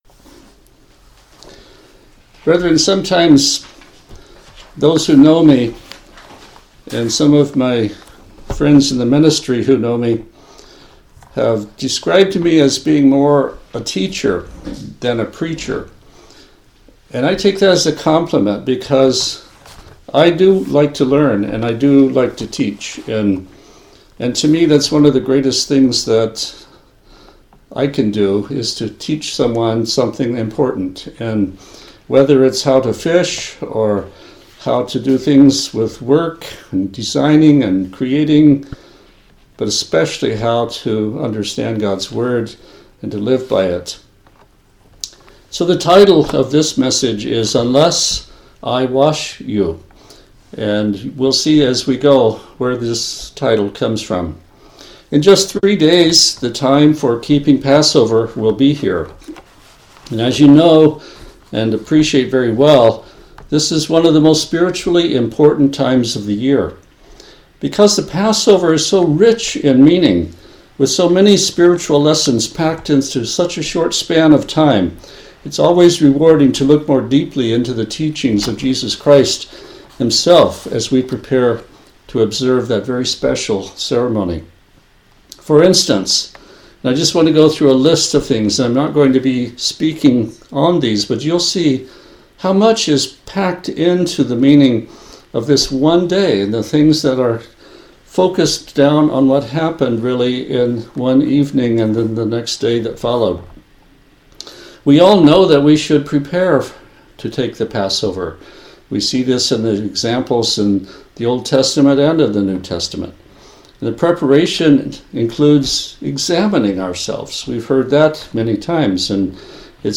In this sermon we'll consider some of the profoundly important meaning of Jesus' teaching.
Given in Olympia, WA Tacoma, WA